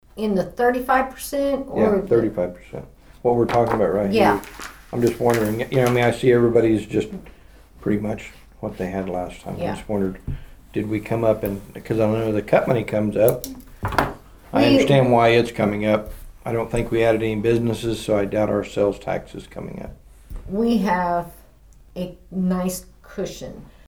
The Nowata County Commissioners met on Monday morning at the Nowata County Annex.
Commissioner Troy Friddle and County Clerk Kay Spurgeon talked about the sales tax.